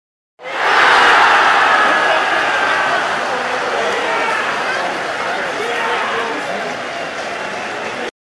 Вопль ликующей толпы после забитого гола